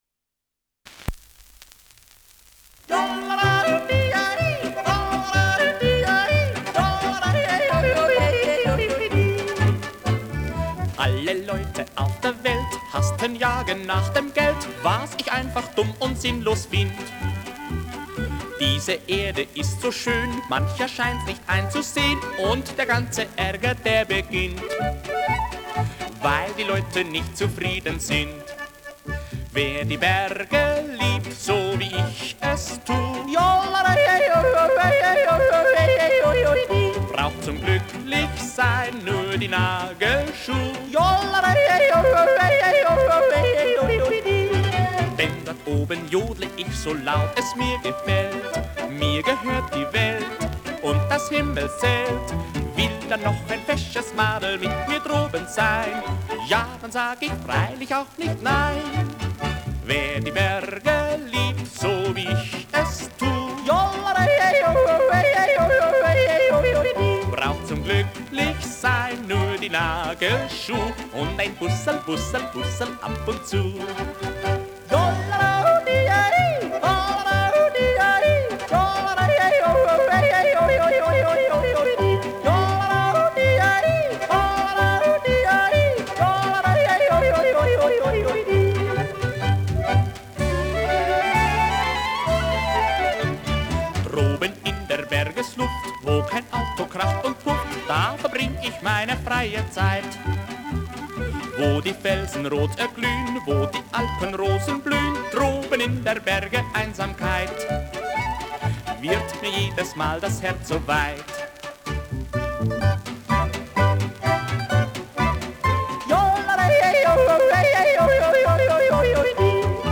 Schellackplatte
Folkloristisches Ensemble* FVS-00015